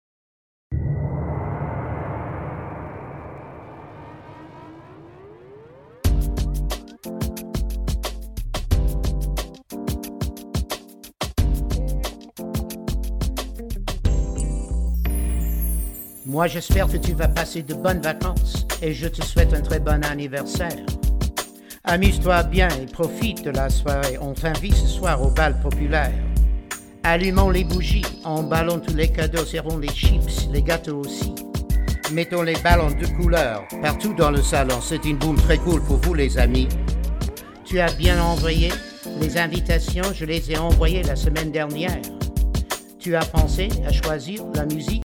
French Language Raps